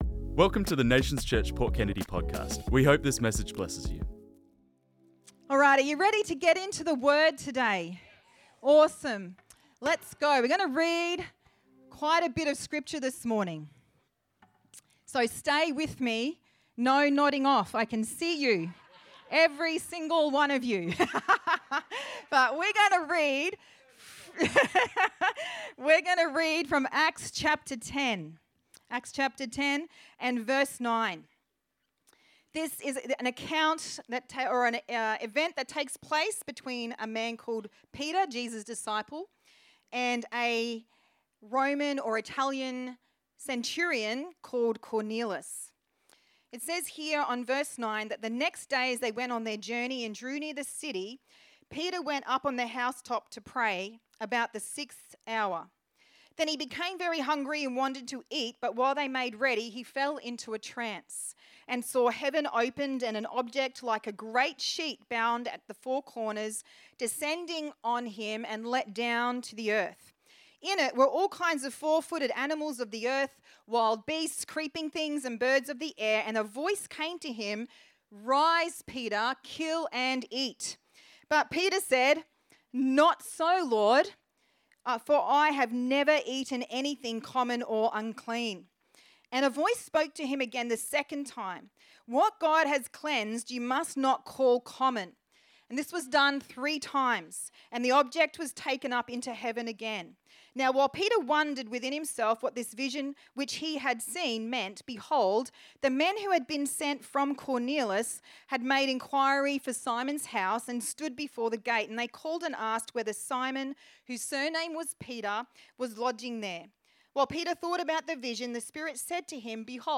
This message was preached on Sunday 2nd February 2025